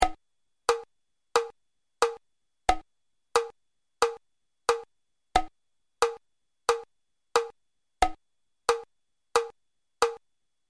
Für die Hörbeispiele habe ich einen einfachen 4/4 Takt gewählt.
andantino
etwas schneller, leicht bewegt
BPM: 90